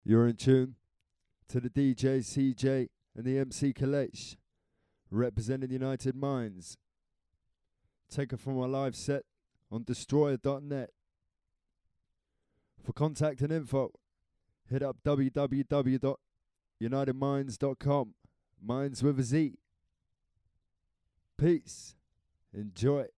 DRUM + BASS
fresh tunes and DJ + MC interaction.